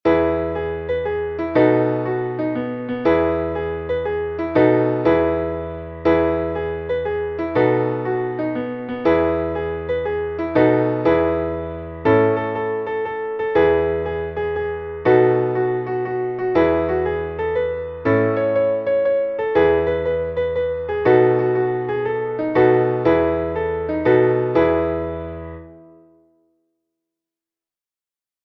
Traditional Norwegian Birthday Song